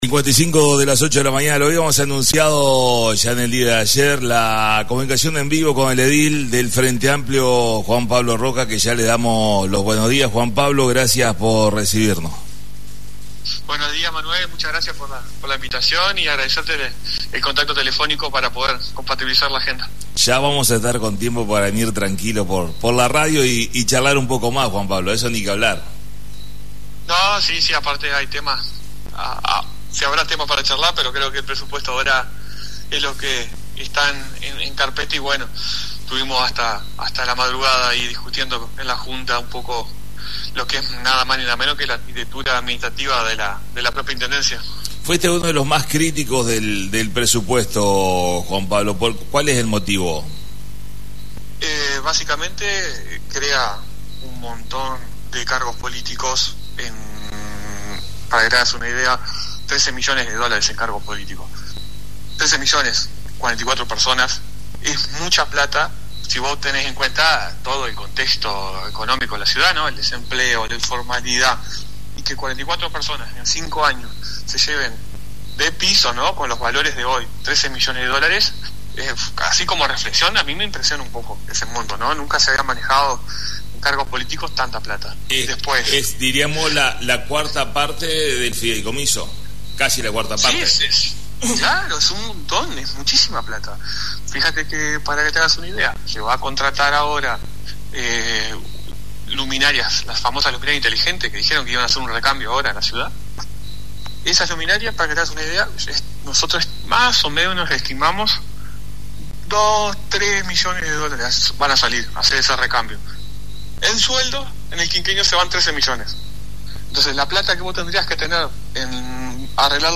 Comunicación en vivo con Juan Pablo Rocca Edil Frente Amplio, hablamos sobre la actualidad, el presupuesto y más.